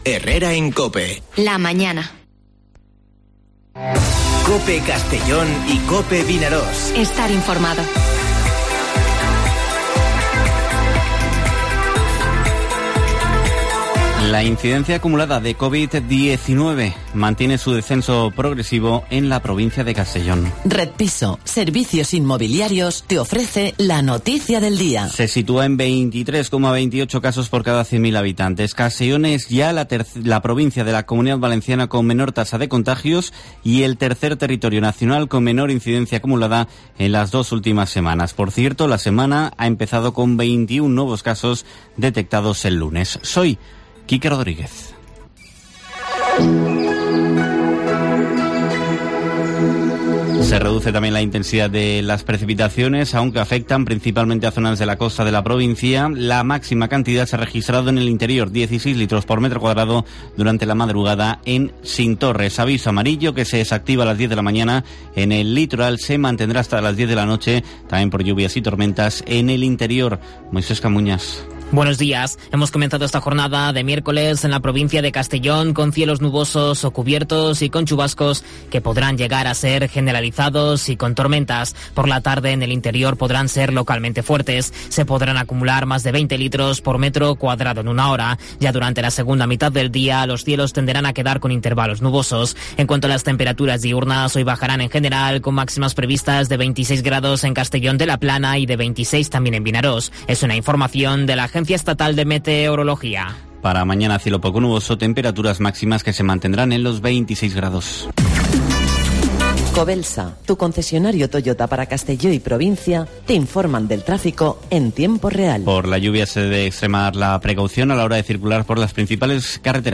Informativo Herrera en COPE en la provincia de Castellón (23/06/2021)